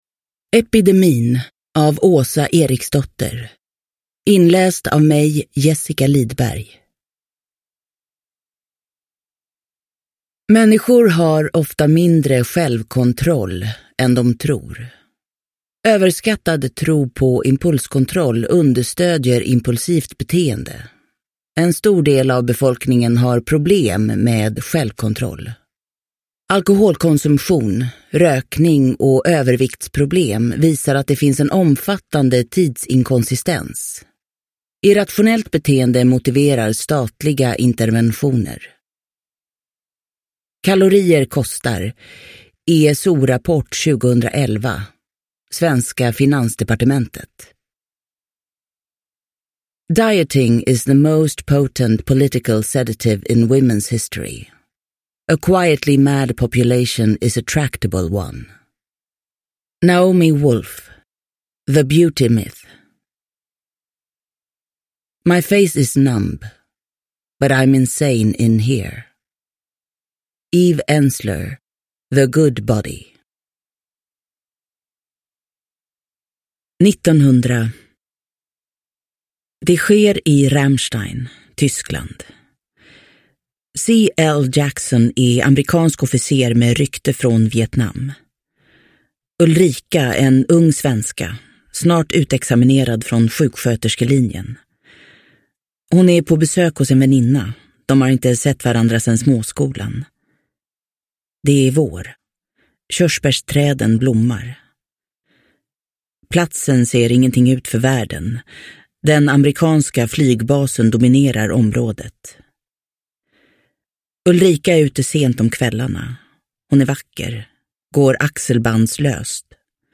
Epidemin – Ljudbok – Laddas ner
Uppläsare: Jessica Liedberg